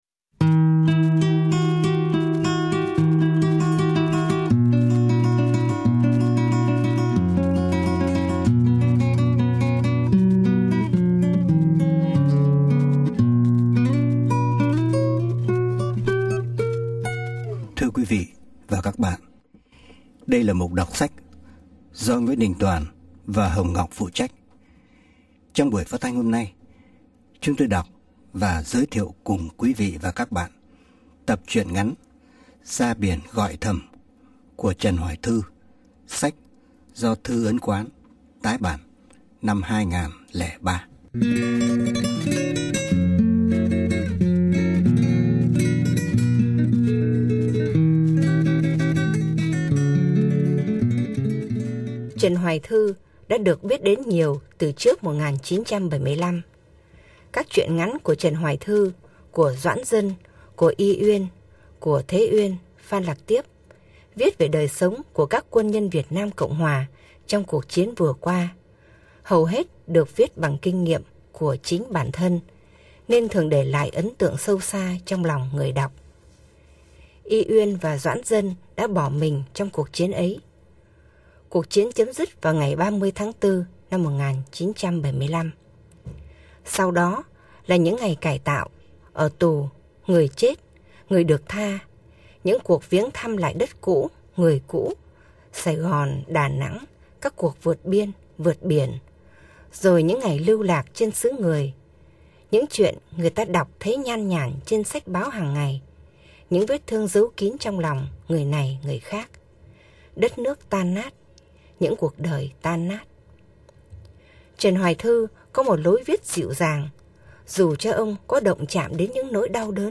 Giọng NĐT không còn là giọng một thanh niên ủ rũ héo sầu ẩm ướt sương buồn tới nỗi sém nhão nhẹt, và những lời ông viết không còn là những lời diễm ảo cho tới nỗi sém cải lương. Phải nghe mới biết, NĐT như một ca sĩ biết truyền cảm xúc cho người nghe khi hát, ở cái cách nhấn chữ nhả câu, ở những khoảng cắt ngừng hay lơi giọng, đây _ một người đàn ông trải đời với hồn cảm nhận thâm sâu, nói những lời đau mong với tới muôn ngàn những bóng người vô danh trong cuộc sống.